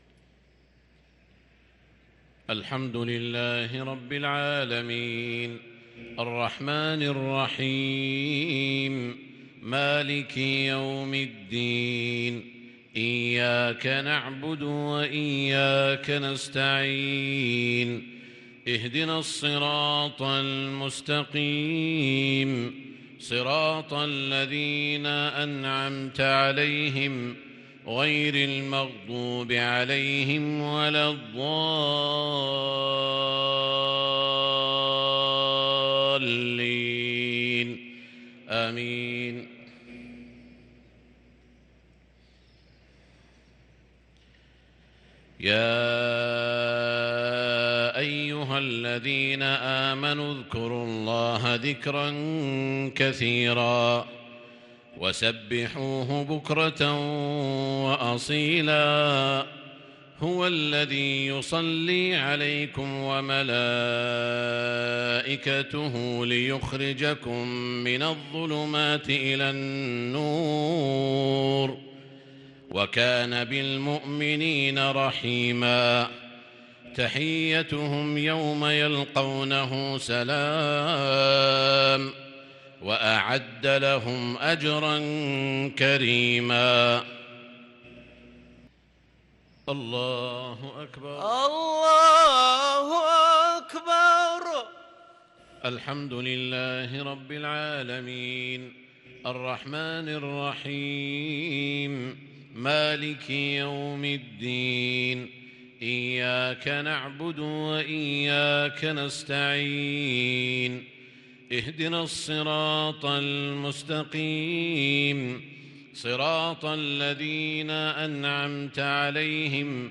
صلاة المغرب للقارئ سعود الشريم 10 ربيع الأول 1444 هـ
تِلَاوَات الْحَرَمَيْن .